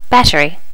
Additional sounds, some clean up but still need to do click removal on the majority.
battery.wav